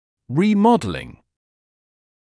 [ˌriː’mɔdlɪŋ][ˌриː’модлин]ремоделирование, изменение формы (также remodelling)